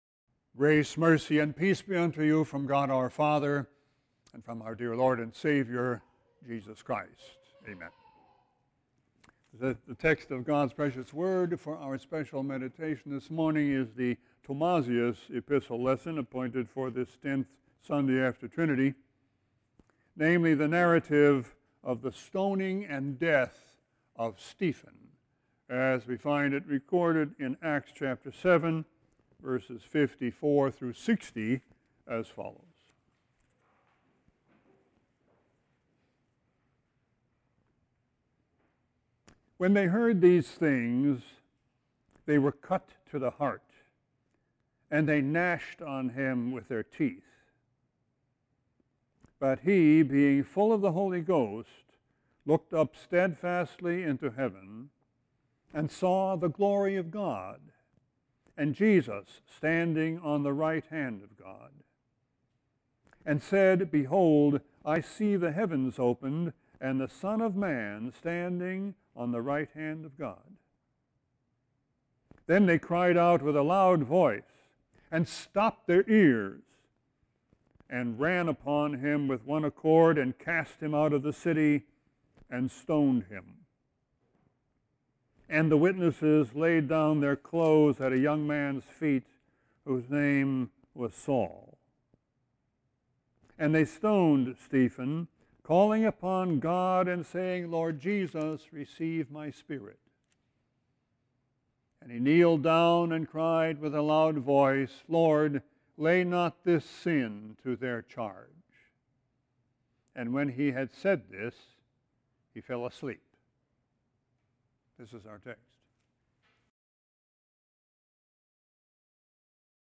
Sermon 8-5-18.mp3